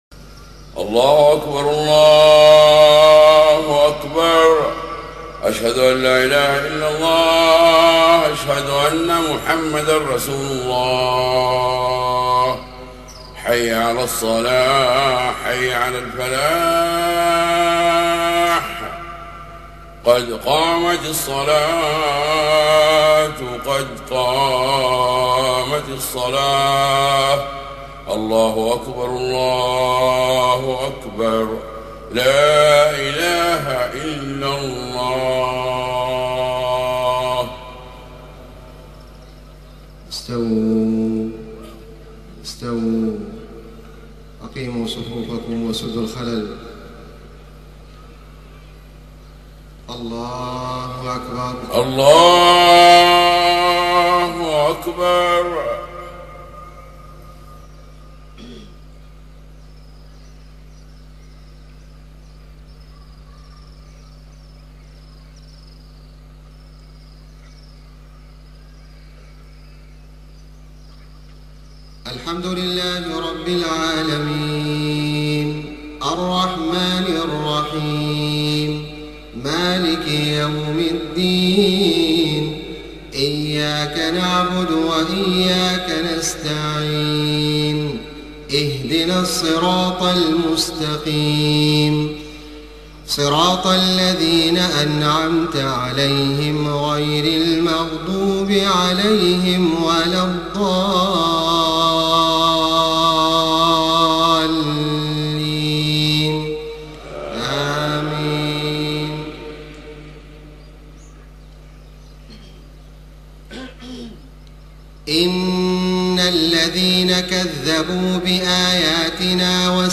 صلاة الفجر 1 ربيع الأول 1430هـ من سورة الأعراف 40-53 > 1430 🕋 > الفروض - تلاوات الحرمين